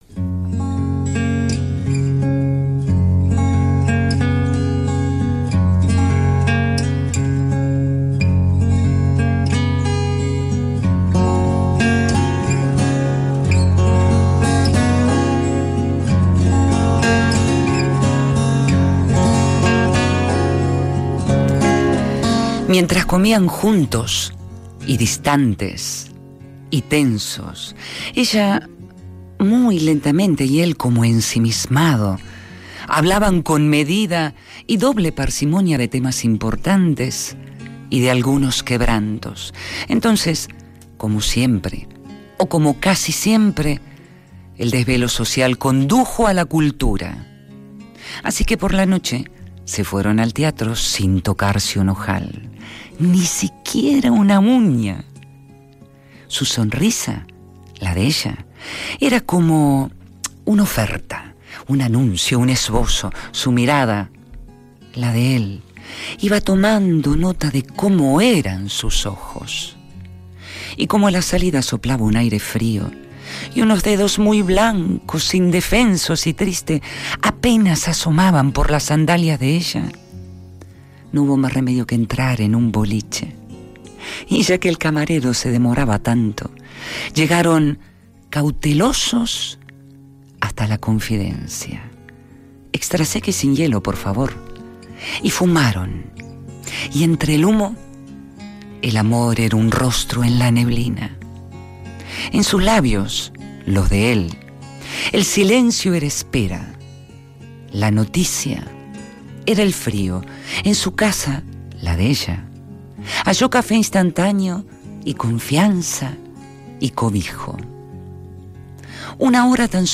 Rapsodeando a Mario Benedetti. Poema: Los formales y el frío.